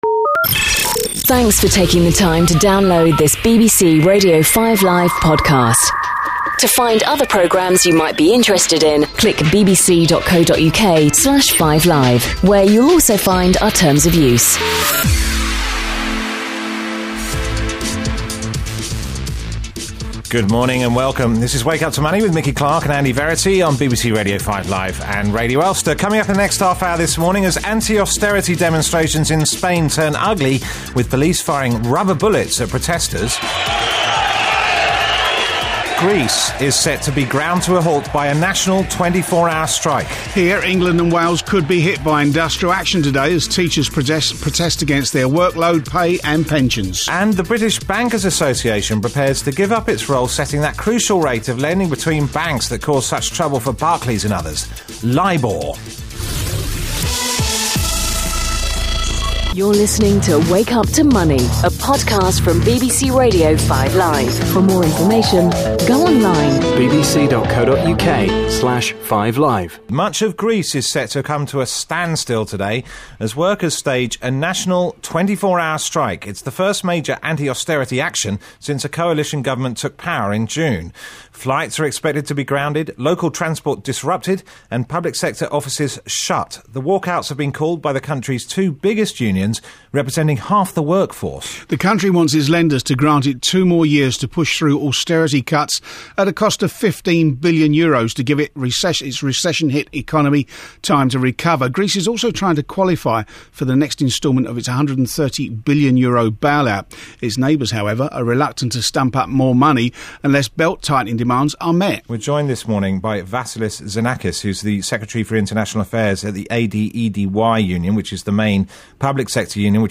Television and radio interviews